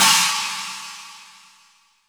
• Crash One Shot C# Key 06.wav
Royality free cymbal crash sound tuned to the C# note. Loudest frequency: 4609Hz
crash-one-shot-c-sharp-key-06-j58.wav